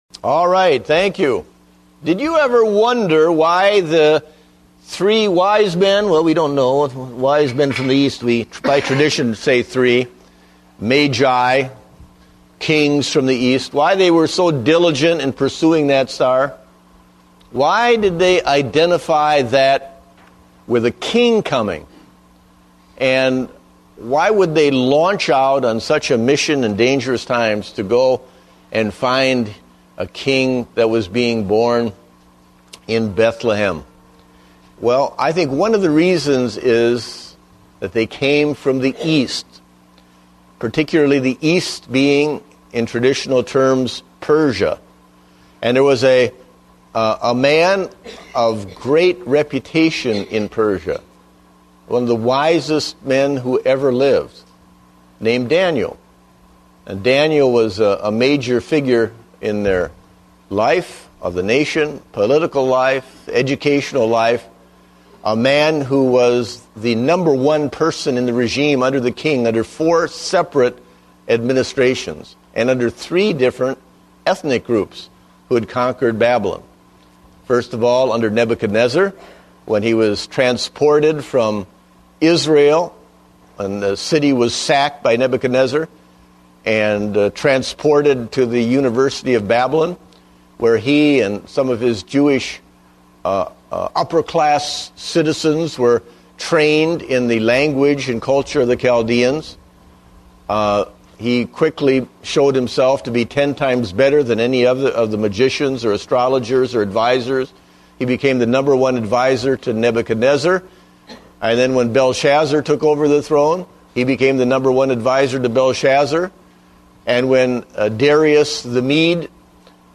Date: December 20, 2009 (Adult Sunday School)